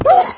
sounds / mob / wolf / hurt1.ogg
hurt1.ogg